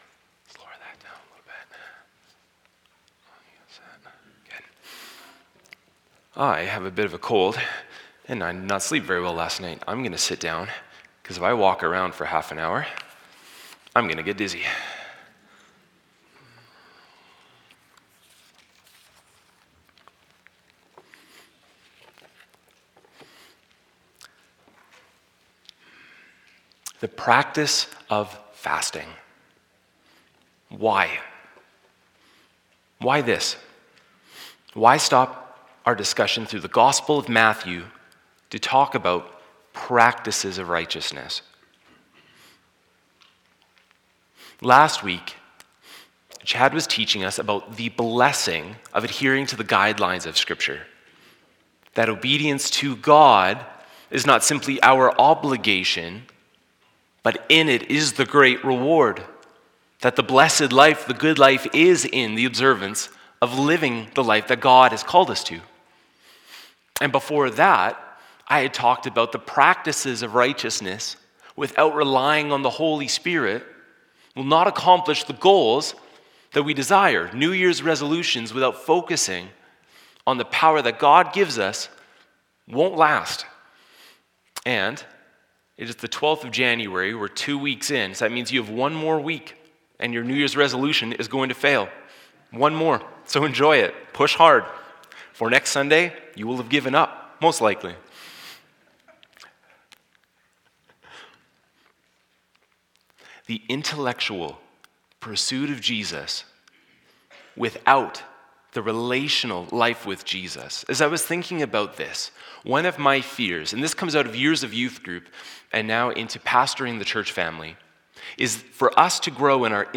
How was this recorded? Bridgeway Community Church